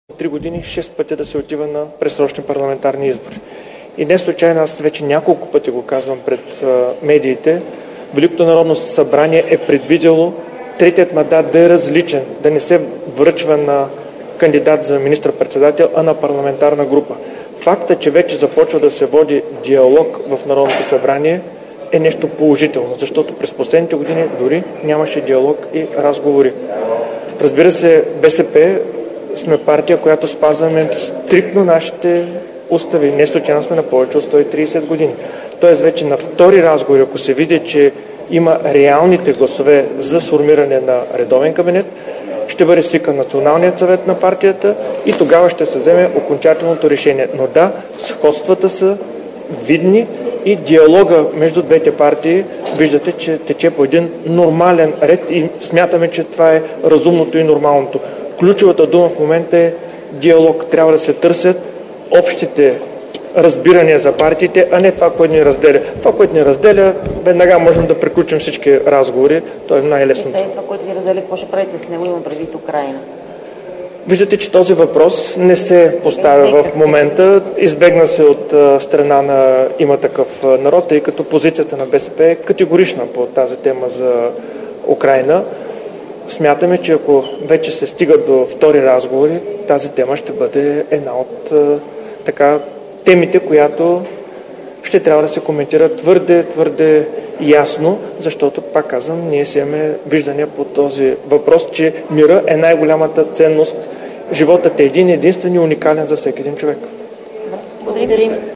3. Изслушване на г-н Делян Пеевски във връзка с дейността на комисията..  директно от мястото на събитието (пл. „Княз Александър I" №1, зала 4)
Директно от мястото на събитието